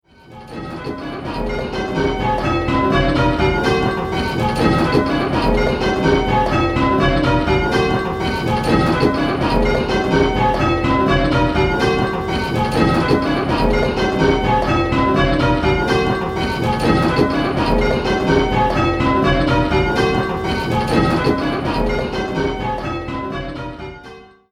In this game you will be answering similar questions, but with 10 bells to choose from, the choices are much larger and there are more different notes to identify!